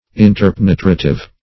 Meaning of interpenetrative. interpenetrative synonyms, pronunciation, spelling and more from Free Dictionary.
interpenetrative.mp3